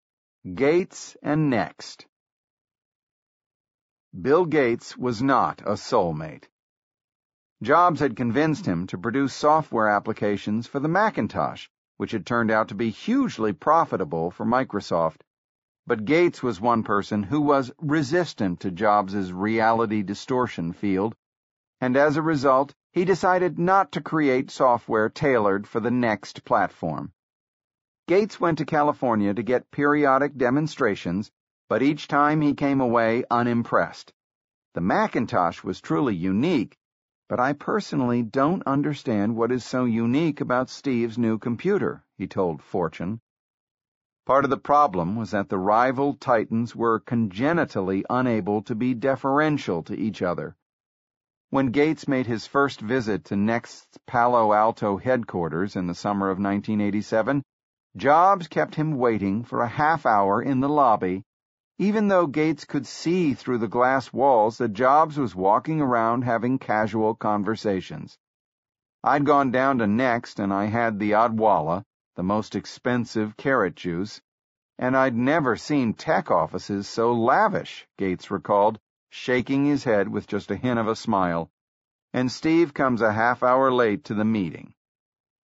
在线英语听力室乔布斯传 第240期:盖茨和NeXT的听力文件下载,《乔布斯传》双语有声读物栏目，通过英语音频MP3和中英双语字幕，来帮助英语学习者提高英语听说能力。
本栏目纯正的英语发音，以及完整的传记内容，详细描述了乔布斯的一生，是学习英语的必备材料。